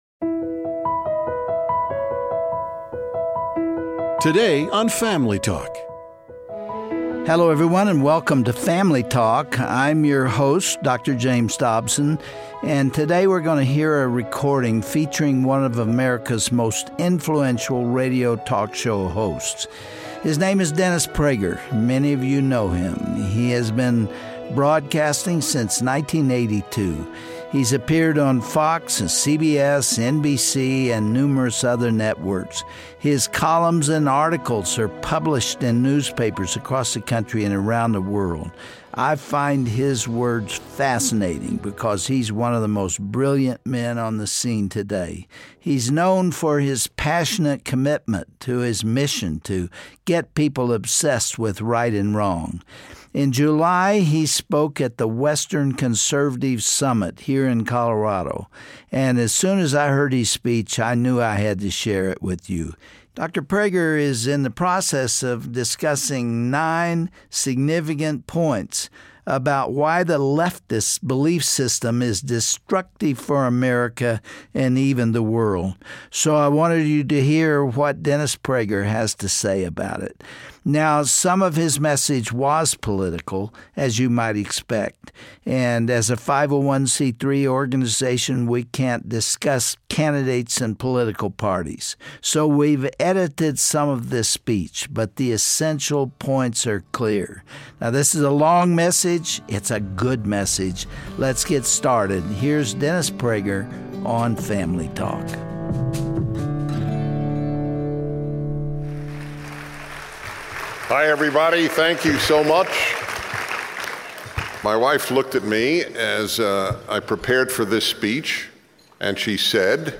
Over the years, America has been leaning more and more to the left, but what does that really mean for our country? Dennis Prager speaks candidly and passionately about the ways leftism debases a culture over time, and he warns that the dangers of leftism arent limited to any particular candidate.